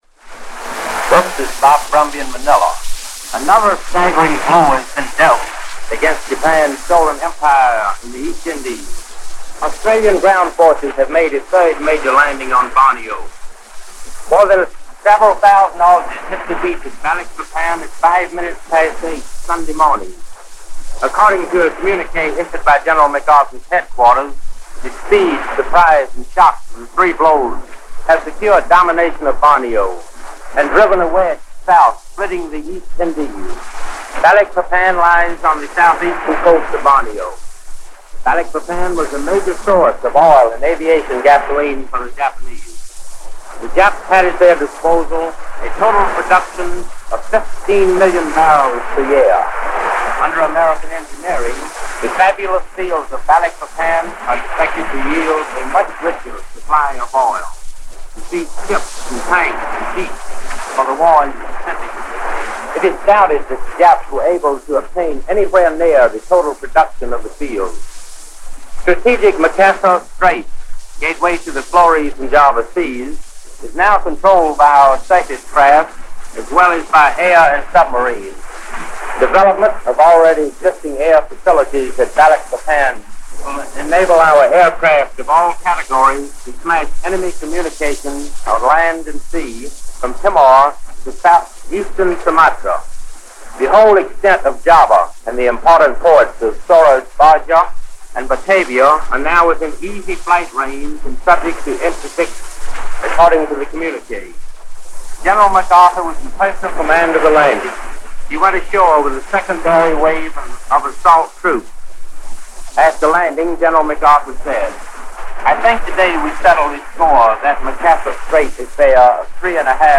Here are two reports from the South Pacific, from July 1, 1945.